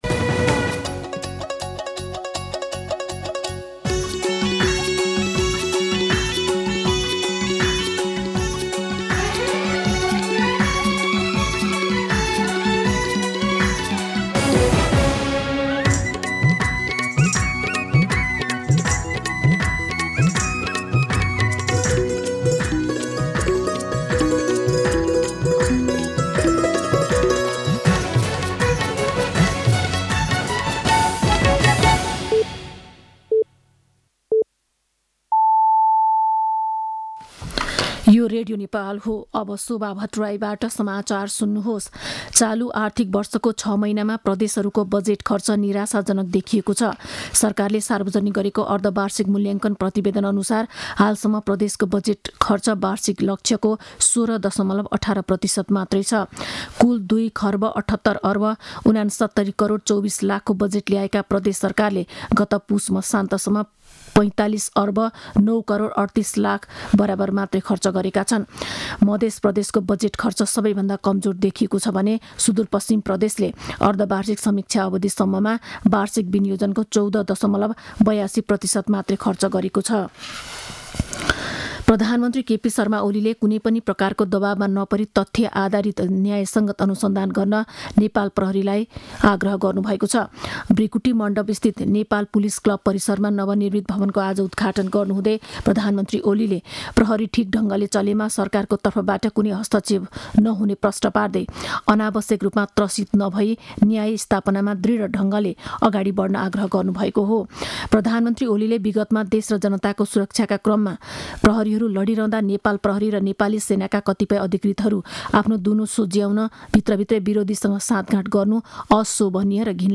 दिउँसो ४ बजेको नेपाली समाचार : २७ माघ , २०८१